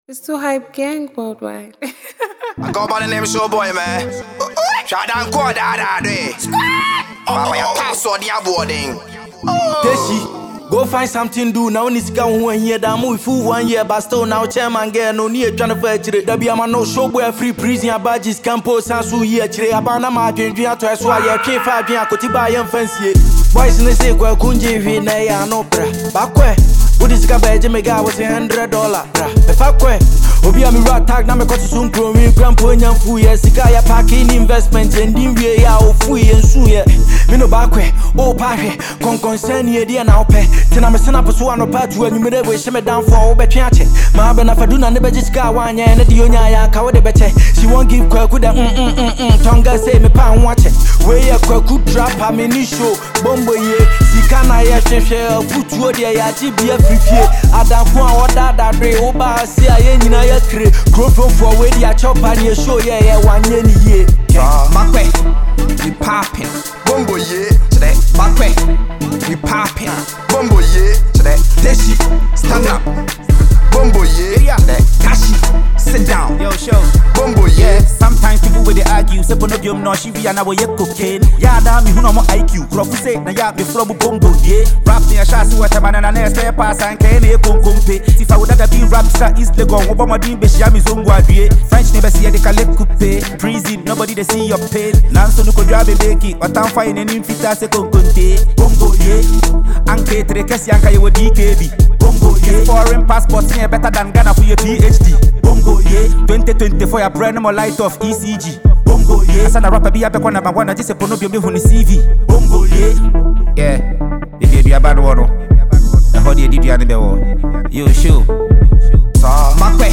This is a banger all day.